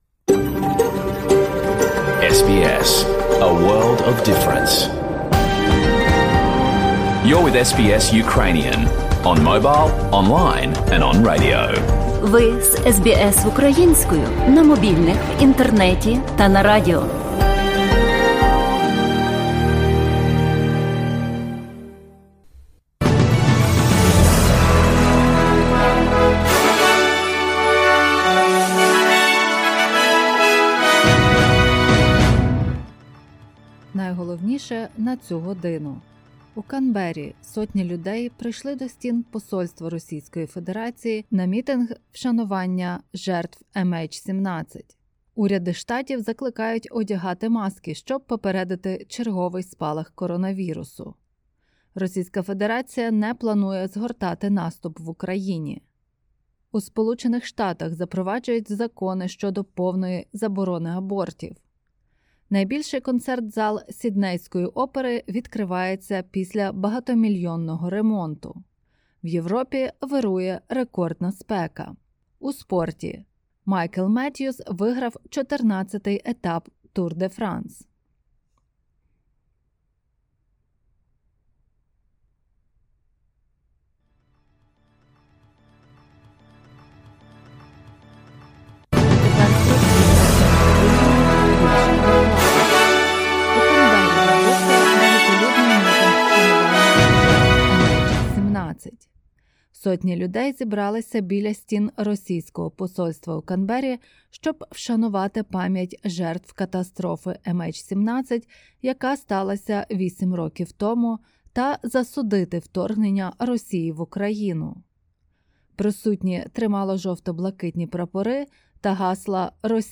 Бюлетень SBS новин українською мовою.